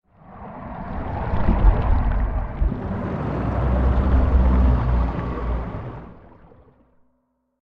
File:Sfx creature glowwhale gulp 01.ogg - Subnautica Wiki
Sfx_creature_glowwhale_gulp_01.ogg